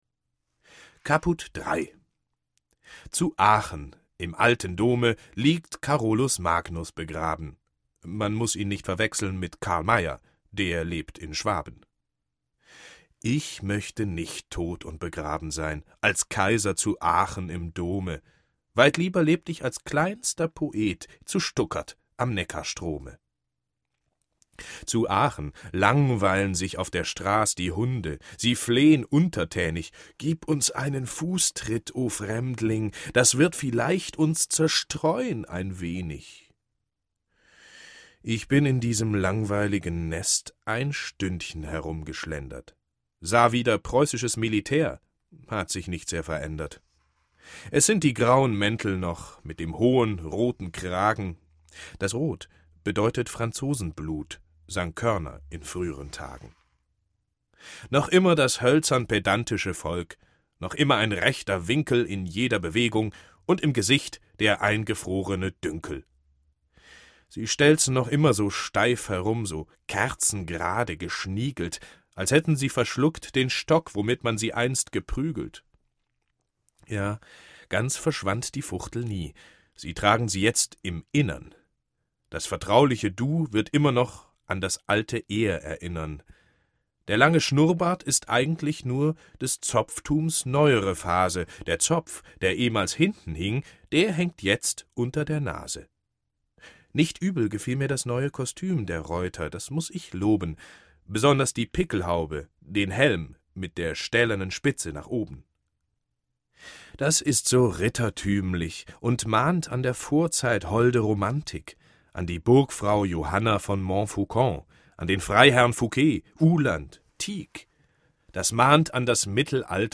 Deutschland. Ein Wintermärchen - Heinrich Heine - Hörbuch